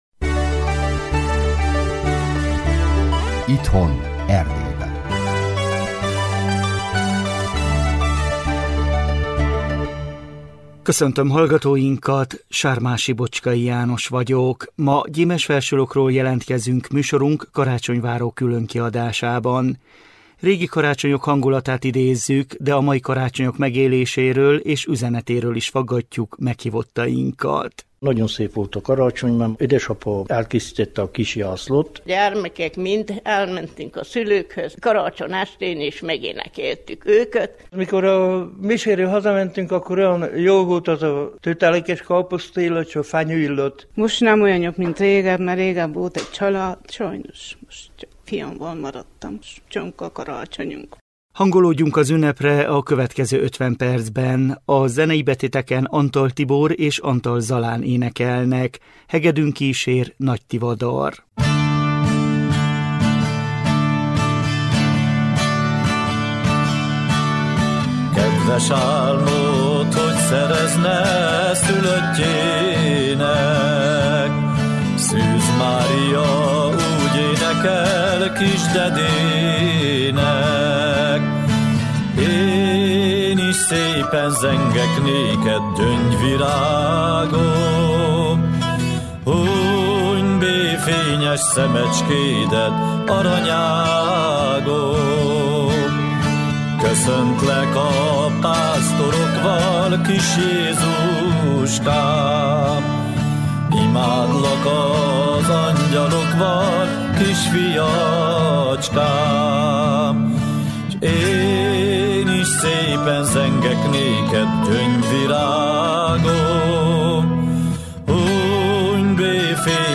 A 2025 december 21-én jelentkező műsorban a Hargita megyei Gyimesfelsőlokról jelentkezünk karácsonyváró különkiadással.